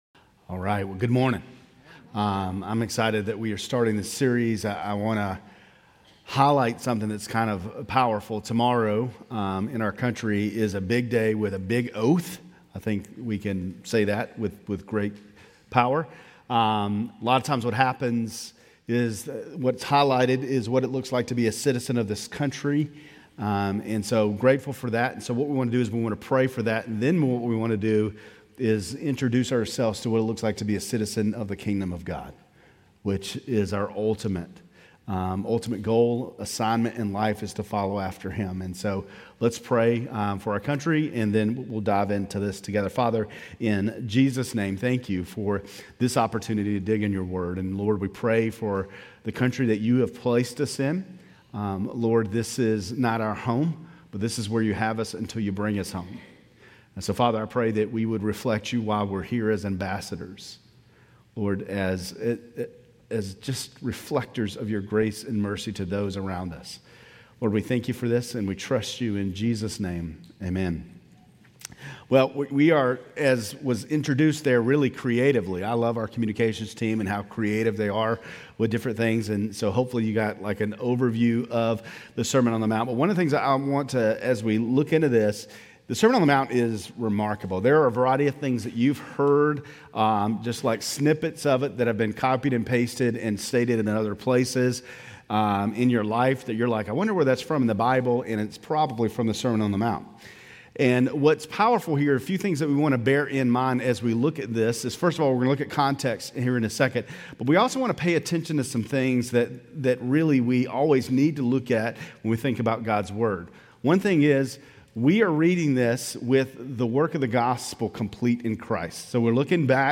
Grace Community Church Lindale Campus Sermons Jan 19 - Lindale Campus Jan 05 2025 | 00:24:28 1x 00:00 / 00:24:28 Subscribe Share RSS Feed Share Link Embed